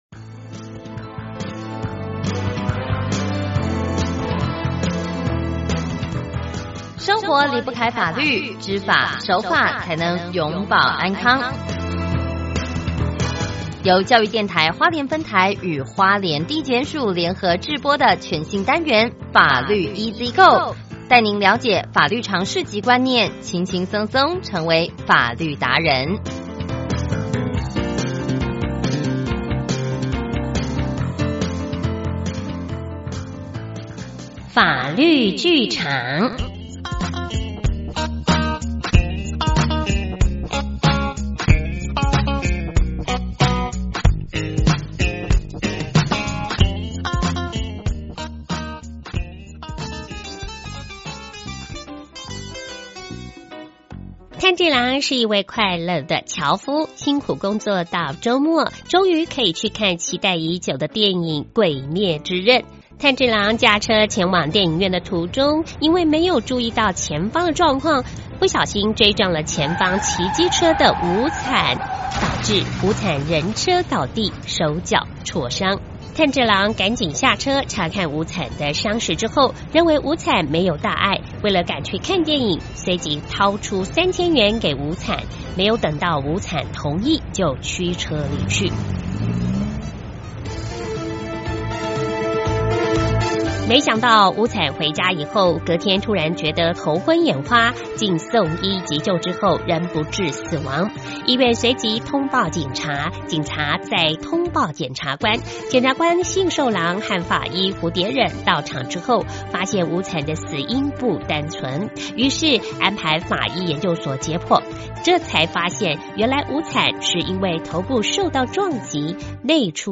炭治郎駕車前往電影院的途中，因沒有注意前方狀況，不小心追撞前方騎機車的「無慘」(撞擊音效)，導致無慘人車倒地，手腳挫傷。
沒想到，無慘回家後，隔天突然覺得頭昏眼花，經送醫急救後(救護車音效)仍不治死亡。